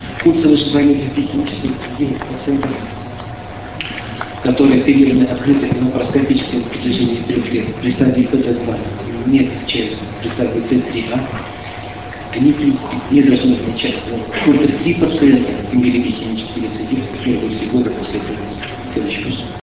5 Российская Школа по эндоскопической и открытой урологии, 8-10 декабря 2004 года.
Лекция: ЛАПАРОСКОПИЯ В УРОЛОГИИ СЕГОДНЯ.